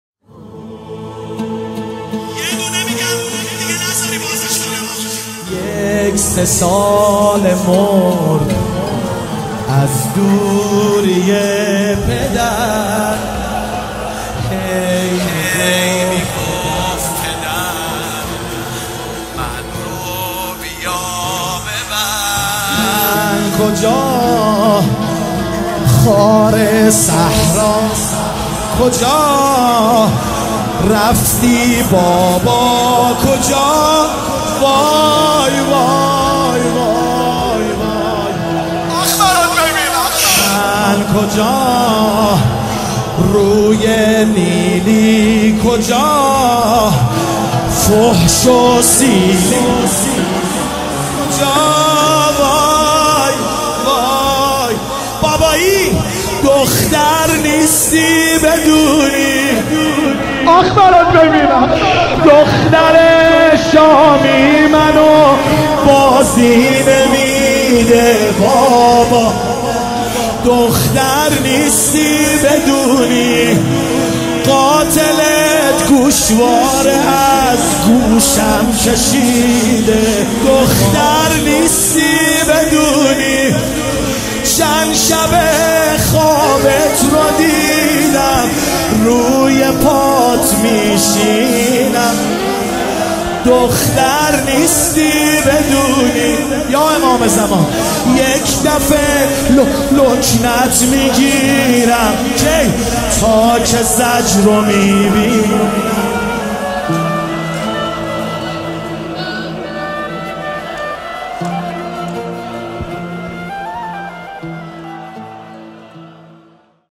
وفات حضرت معصومه (س) | مسجد حضرت معصومه(س) کرج